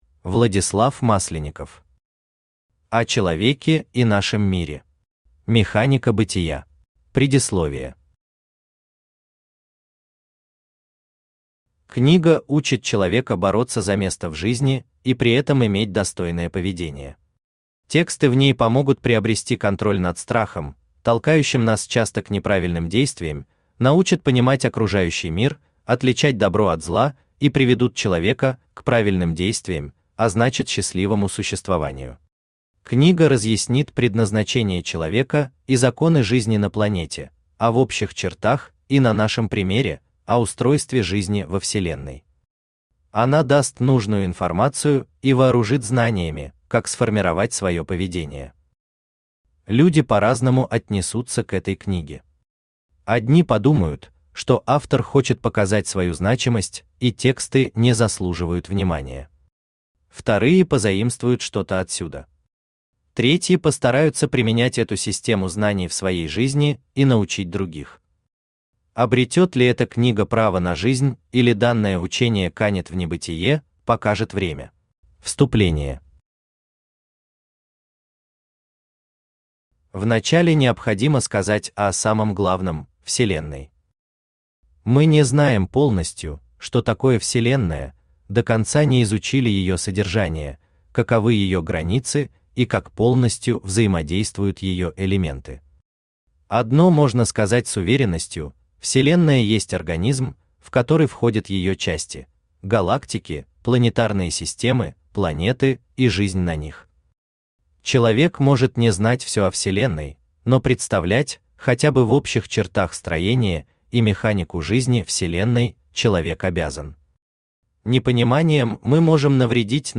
Аудиокнига О человеке и нашем мире. Механика бытия | Библиотека аудиокниг
Механика бытия Автор Владислав Масленников Читает аудиокнигу Авточтец ЛитРес.